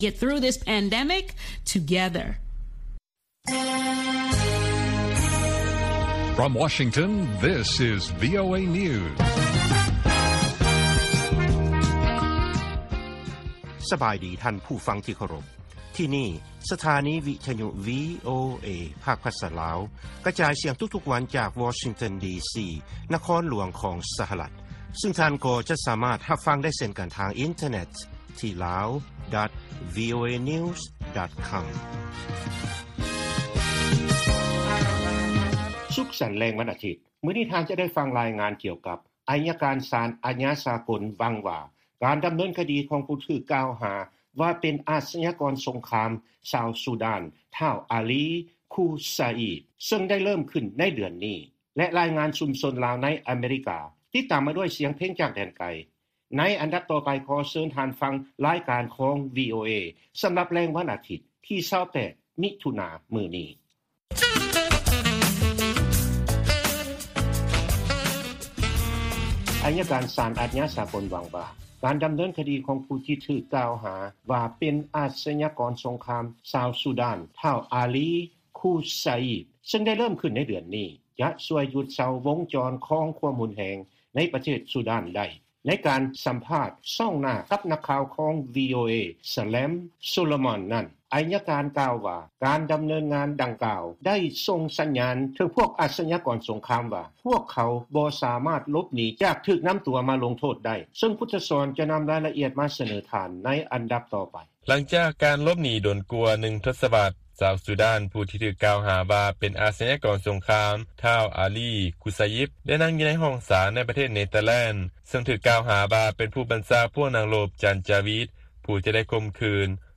ລາຍການກະຈາຍສຽງຂອງວີໂອເອ ລາວ
ວີໂອເອພາກພາສາລາວ ກະຈາຍສຽງທຸກໆວັນ.